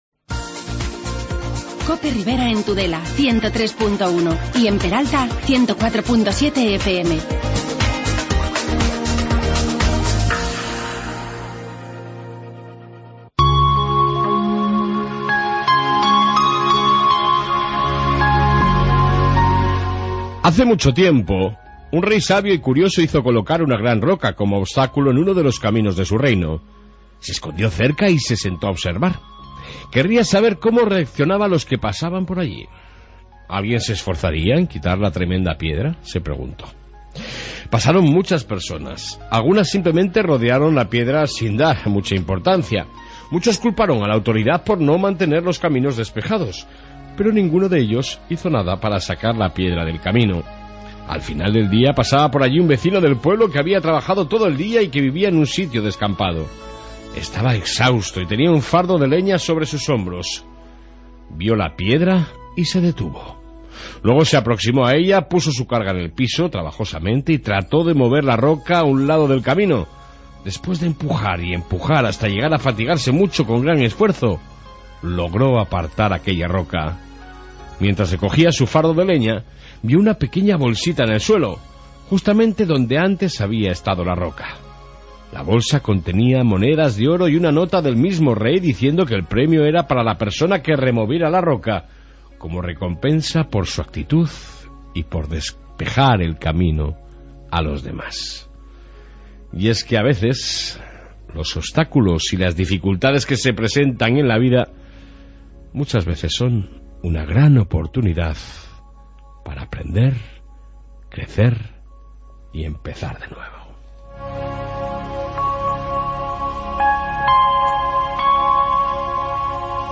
AUDIO: Reflexión matutina, Policía Municipal Y entrevista con el Alcalde de Arguedas Fernando Mendoza